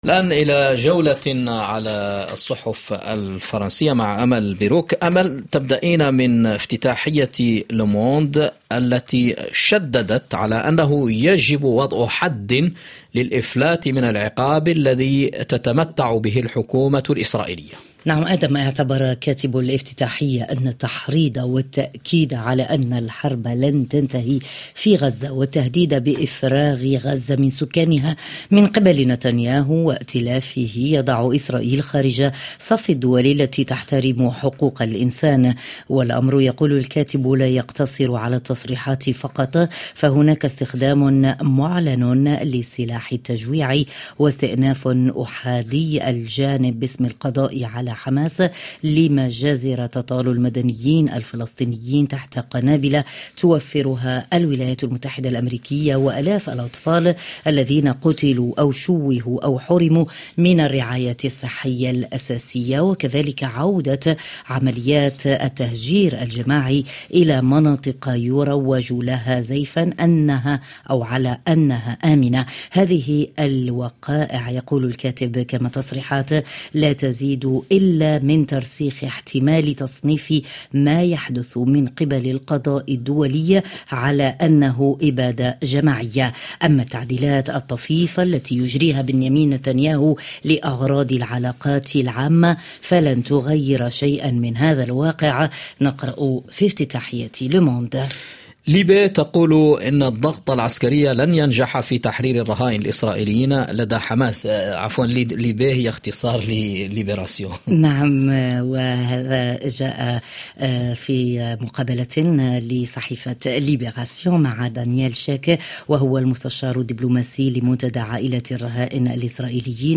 ما لم تقرؤوه في صحف الصباح تستمعون إليه عبر أثير "مونت كارلو الدولية" في عرض يومي صباحي لأهم التعليقات والتحليلات لكل قضايا الساعة في فرنسا والعالم العربي والعالم وحازت على اهتمام الصحف الفرنسية.